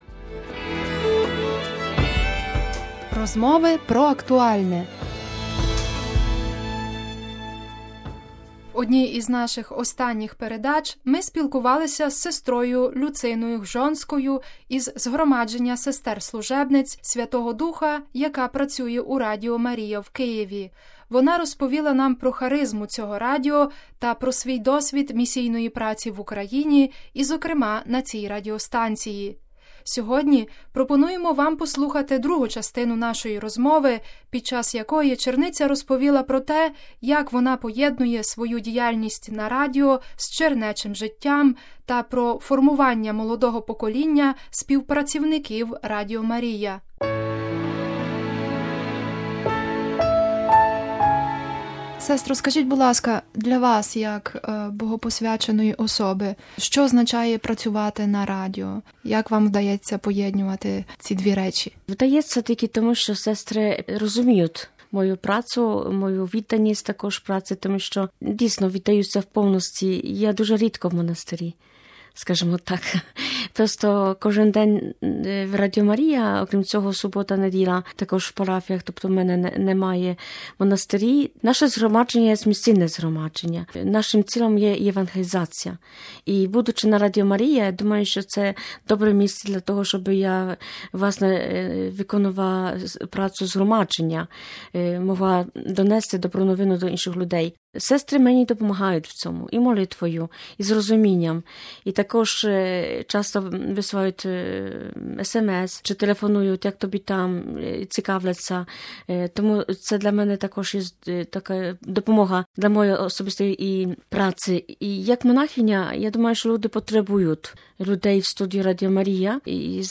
Сьогодні пропонуємо вам послухати другу частину нашої розмови, під час якої черниця розповіла про те, як вона поєднує свою діяльність на Радіо з чернечим життям, та про формування молодого покоління співпрацівників Радіо Марія: